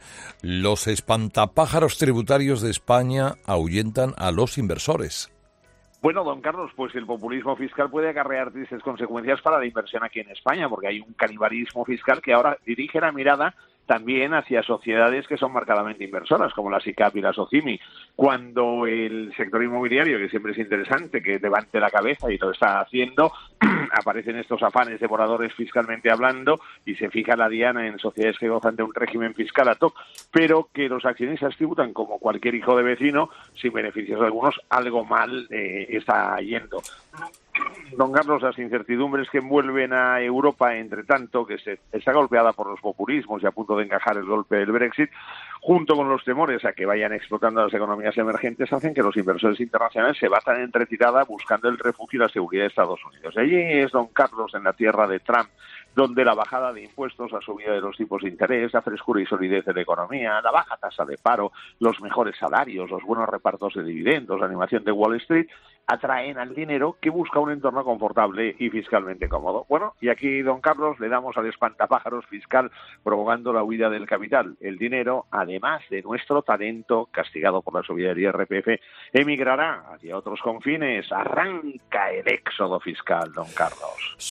Escucha ahora el primer análisis económico de la mañana con el profesor Gay de Liébana en ‘Herrera en COPE’.